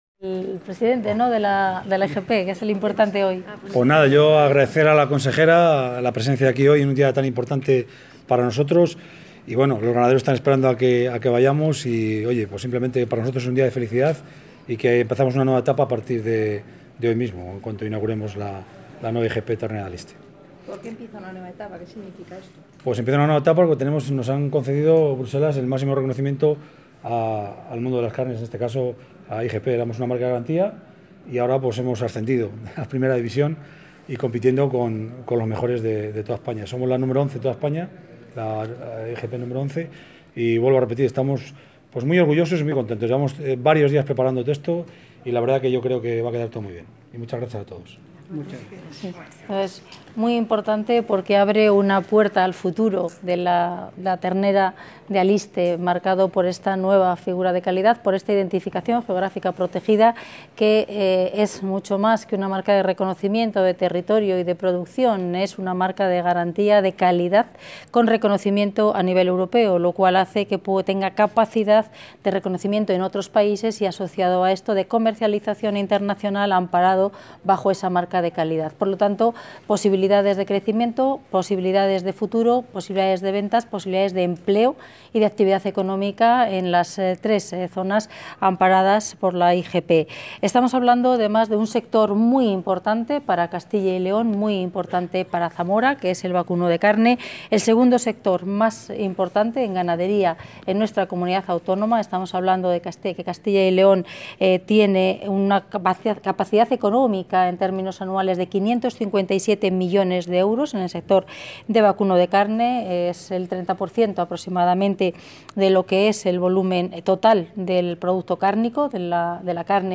Declaraciones de la consejera de Agricultura y Ganadería.